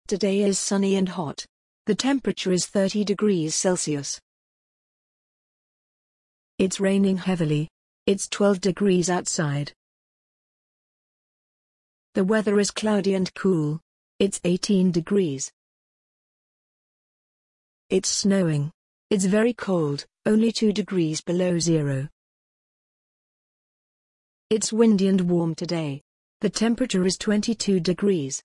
You will hear 5 short weather reports. Listen and write the weather condition mentioned.